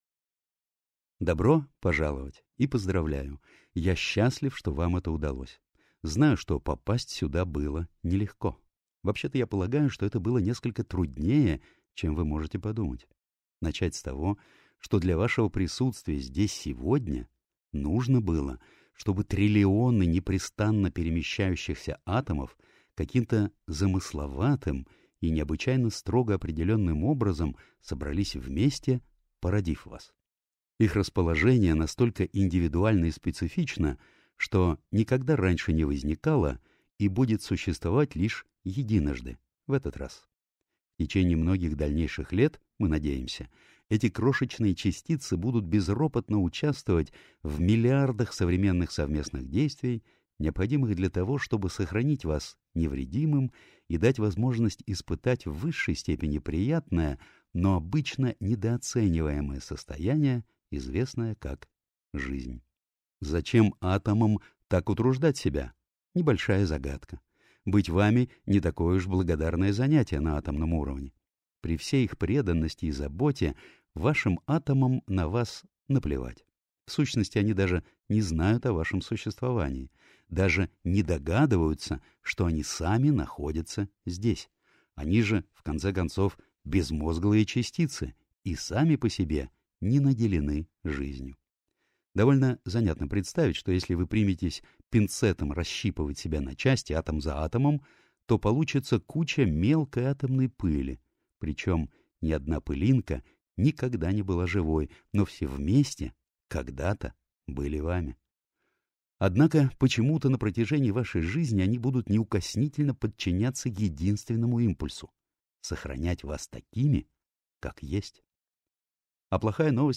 Аудиокнига Краткая история почти всего на свете (часть 1-я) | Библиотека аудиокниг